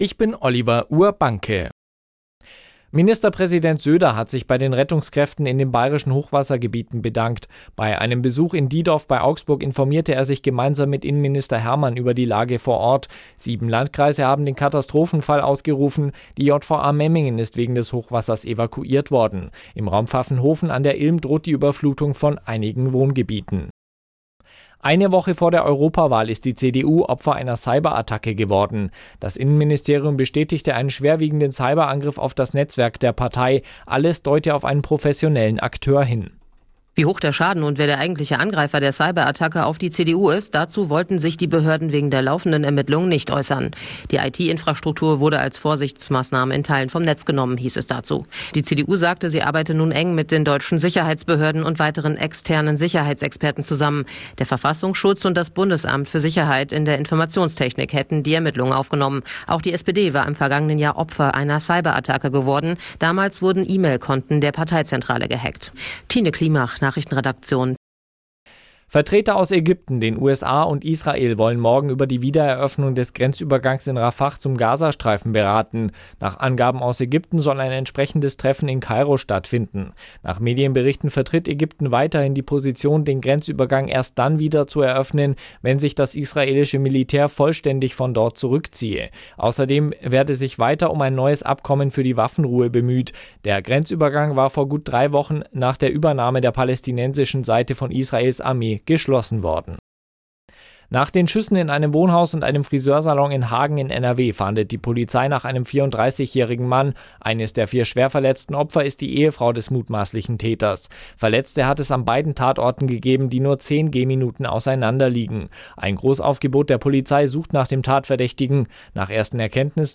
Die aktuellen Nachrichten von Radio Arabella - 01.06.2024